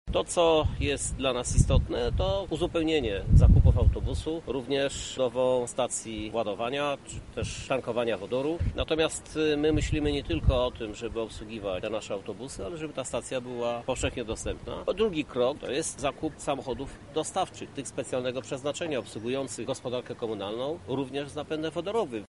Prezydent Miasta Krzysztof Żuk przedstawił kolejne plany inwestycyjne dotyczące transportu: